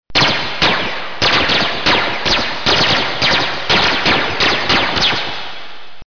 Photon shots
Tags: Photon Sounds Photon Sound Photon clips Sci-fi Sound effects